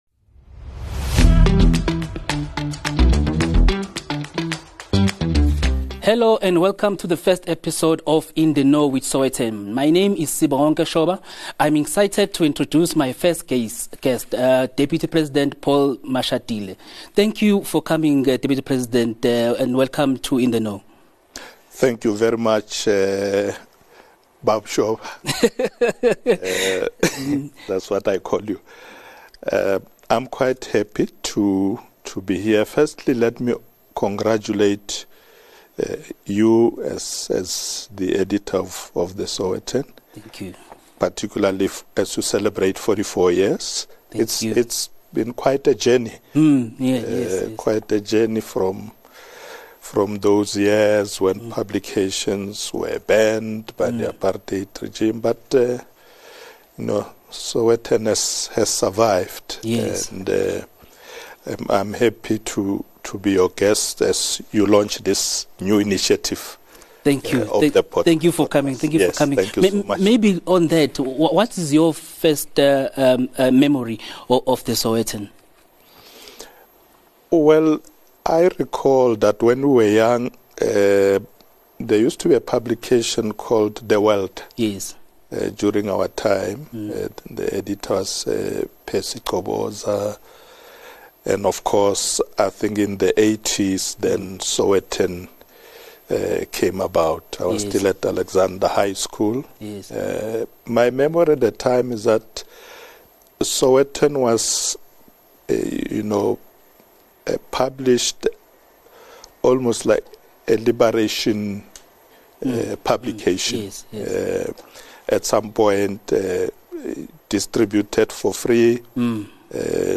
Read more on the Mashatile interview